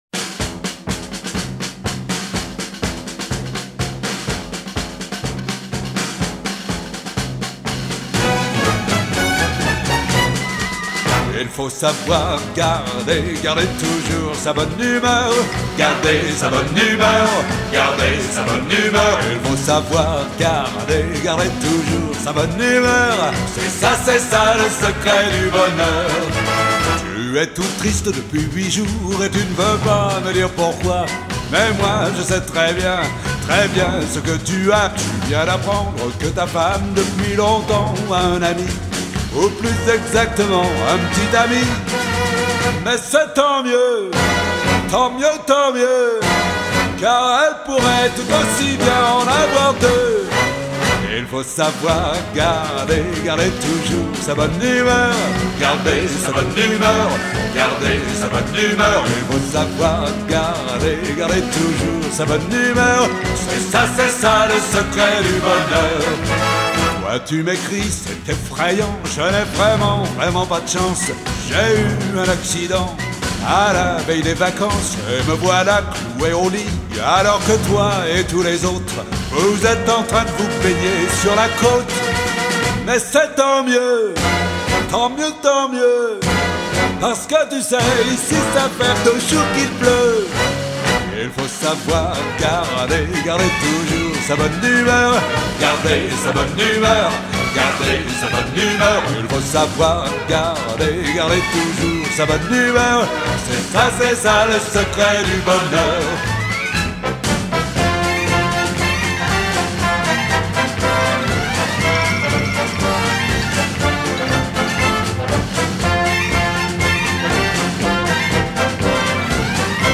Chœur d’hommes fondé en 1860